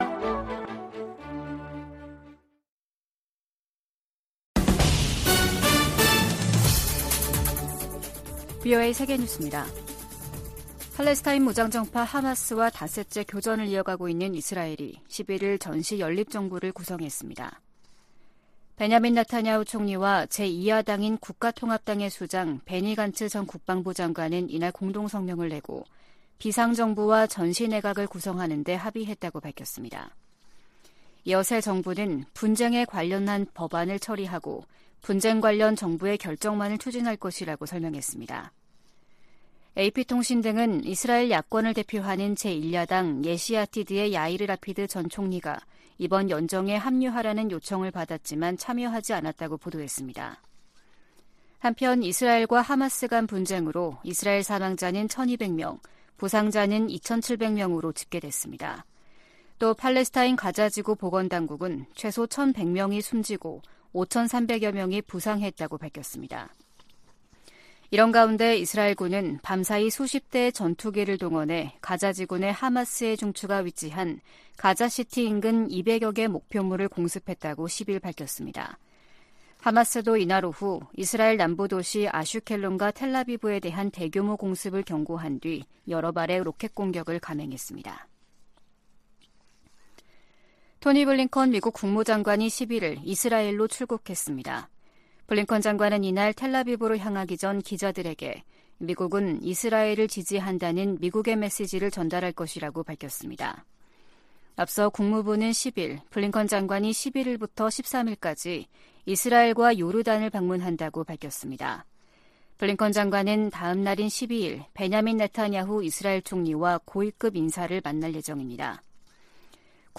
VOA 한국어 아침 뉴스 프로그램 '워싱턴 뉴스 광장' 2023년 10월 12일 방송입니다. 조 바이든 미국 대통령이 이스라엘에 대한 하마스의 공격을 테러로 규정하고 이스라엘에 전폭적 지원을 약속했습니다. 한국 군 당국이 하마스의 이스라엘 공격 방식과 유사한 북한의 대남 공격 가능성에 대비하고 있다고 밝혔습니다. 미 국무부가 하마스와 북한 간 무기 거래 가능성과 관련해 어떤 나라도 하마스를 지원해선 안 된다고 강조했습니다.